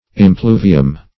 Search Result for " impluvium" : The Collaborative International Dictionary of English v.0.48: Impluvium \Im*plu"vi*um\, n. [L., fr. impluere to rain into; pref. im- in + pluere to rain.]